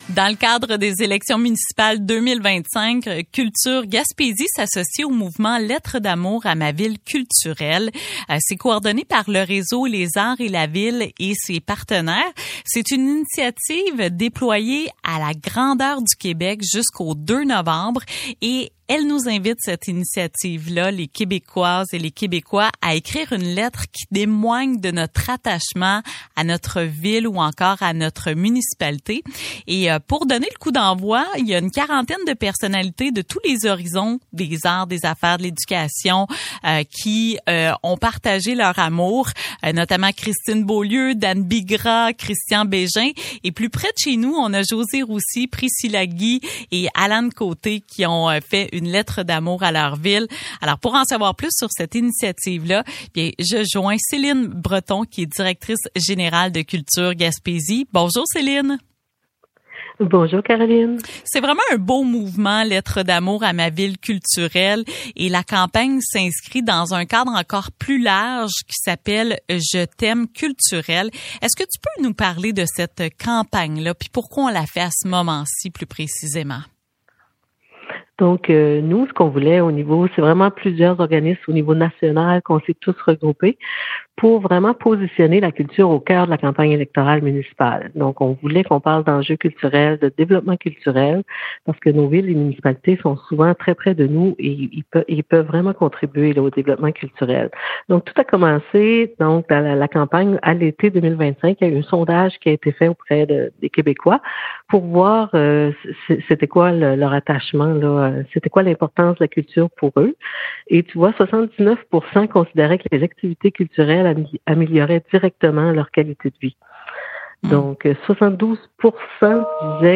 a discuté avec